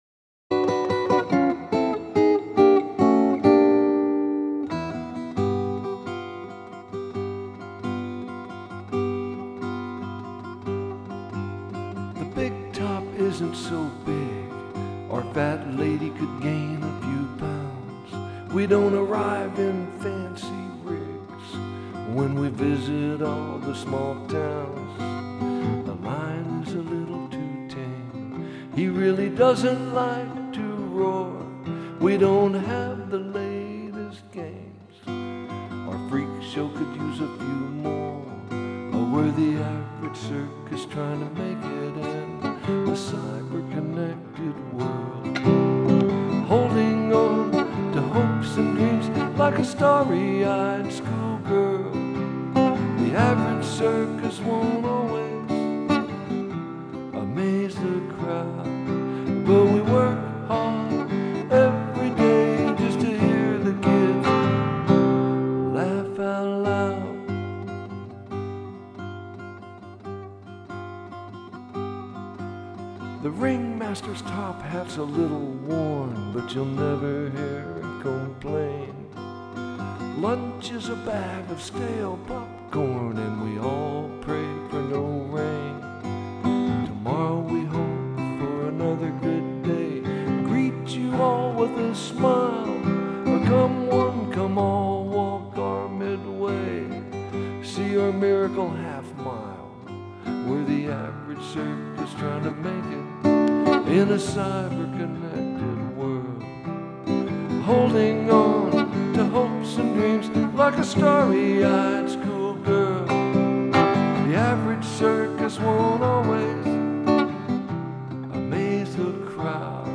Use something from your garbage bin as an instrument
I like the guitar playing. It has a nice warm tone, too.